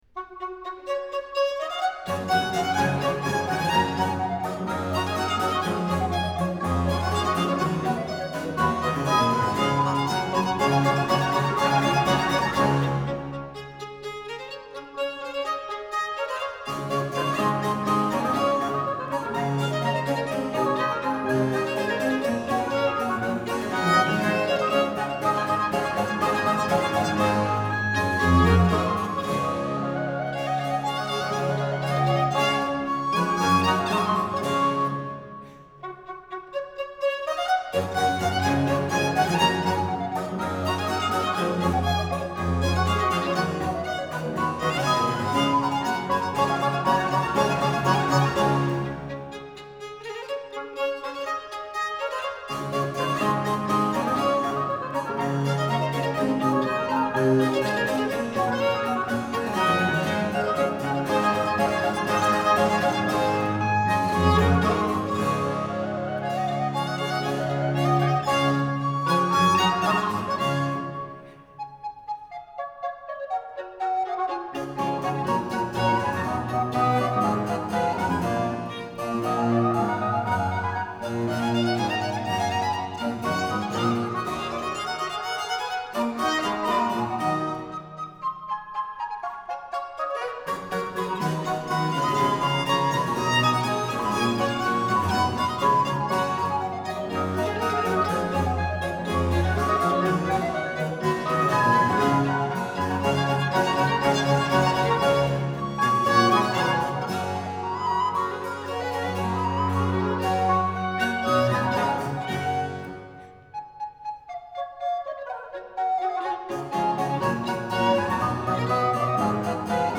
☆巴洛克时期作曲大师的轻快曲目，展现出器乐与合奏之美。